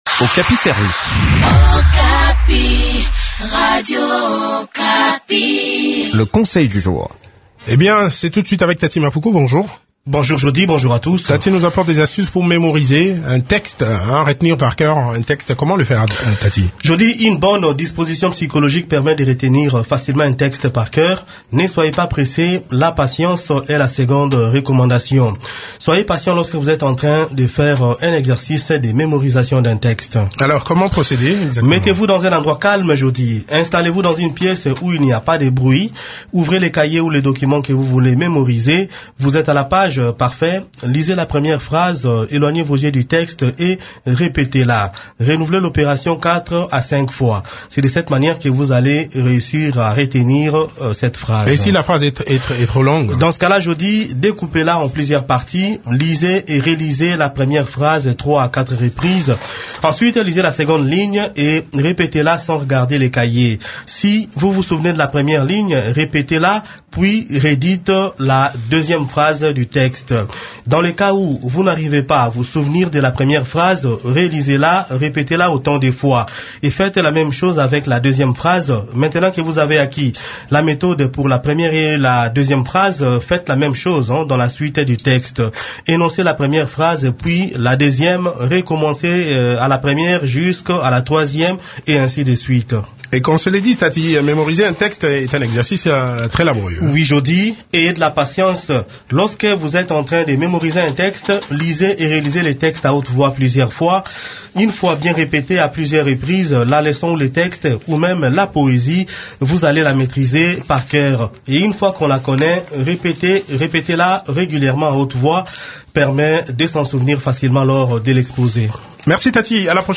Découvrez des astuces qui peuvent vous aider à apprendre un texte par cœur dans cette chronique